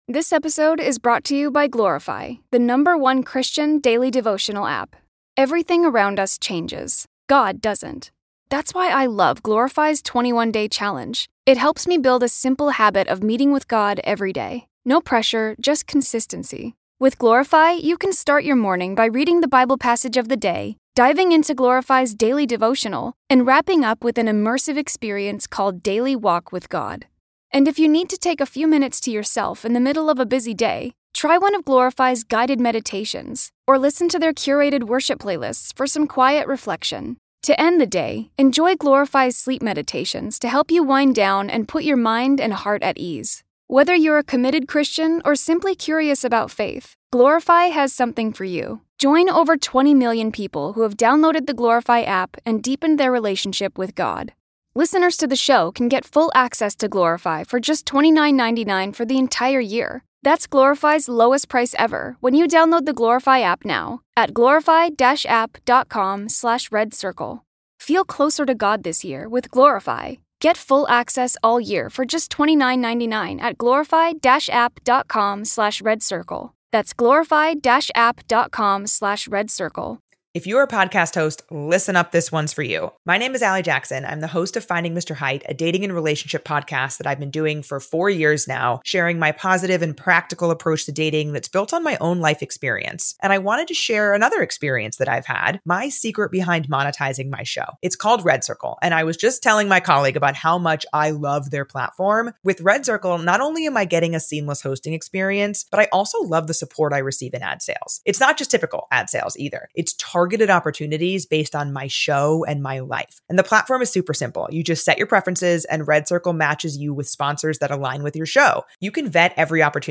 *Disclaimer* Unfortunately, there was a fair bit of rain as this episode was recorded. Please enjoy the unintended ambience of Southeast Asia during the rainy season.